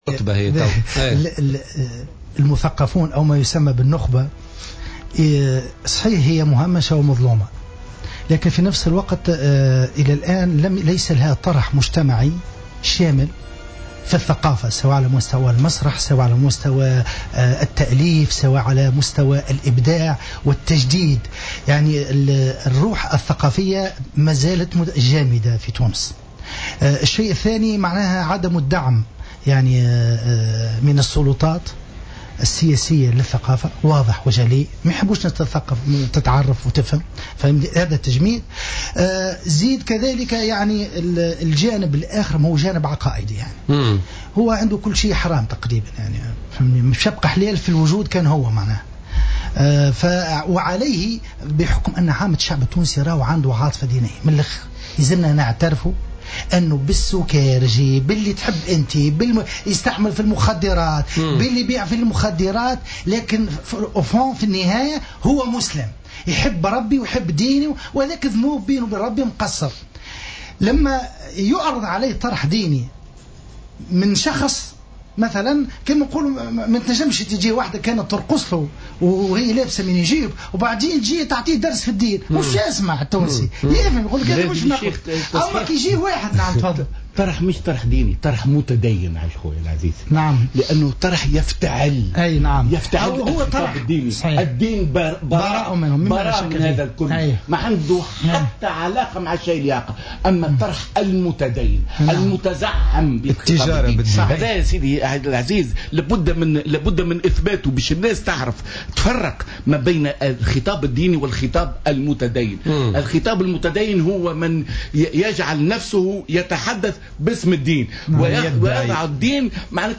ضيف بوليتيكا اليوم الأربعاء